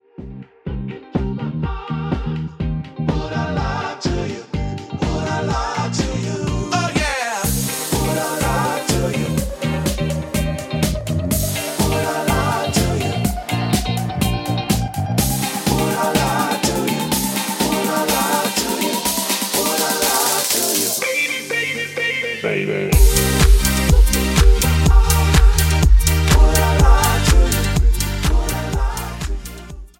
Cm
Backing track Karaoke
Pop, 2010s